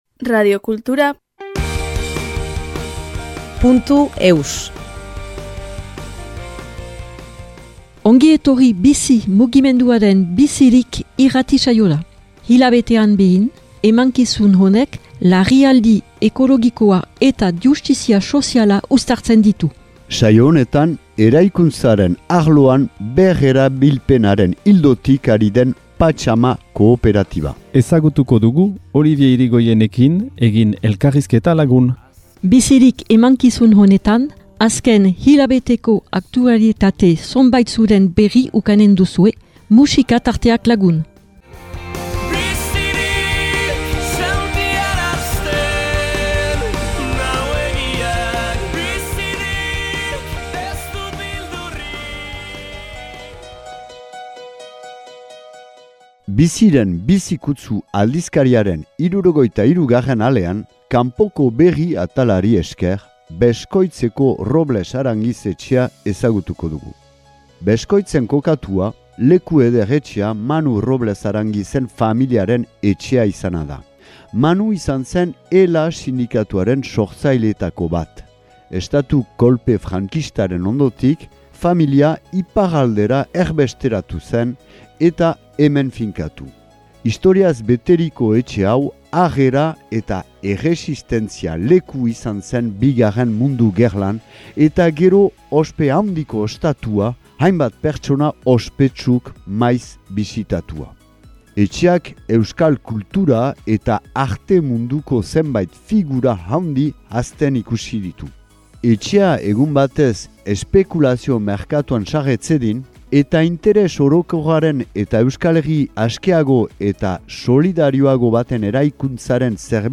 Enregistrement émission de radio en langue basque #134